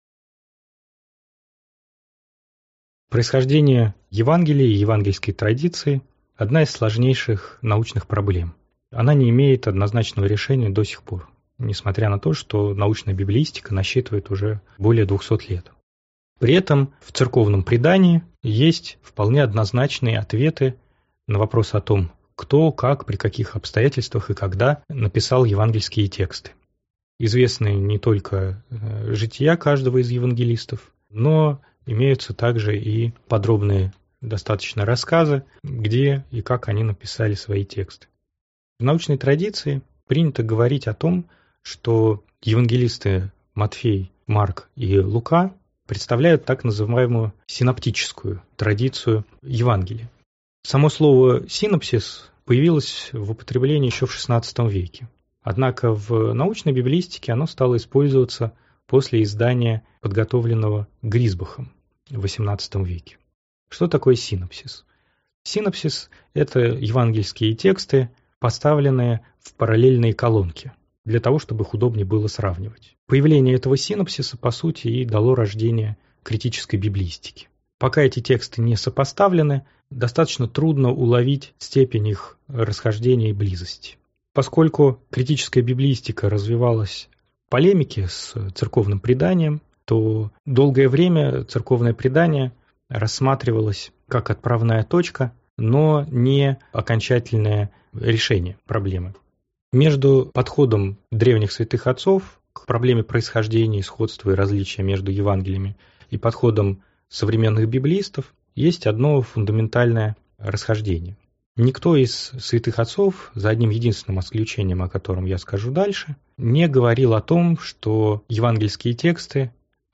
Аудиокнига Происхождение евангелий и синоптическая проблема | Библиотека аудиокниг